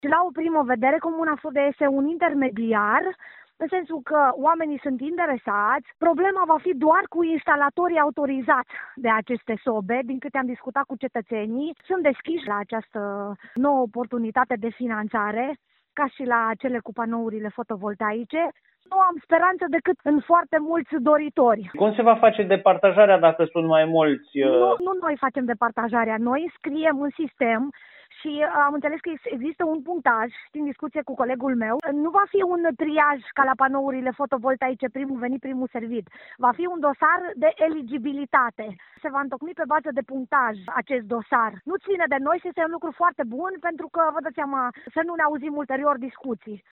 Primarul comunei Fârdea, Violeta Dobrean, a explicat că oamenii interesați se pot adresa primăriilor pentru a-i înscrie în program.